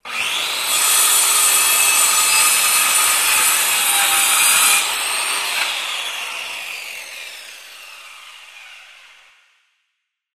金属素材の切断や研削に使う電気工具の「ディスクグラインダー」音です。
使用中は高速回転をしているのでかなりうるさく感じます。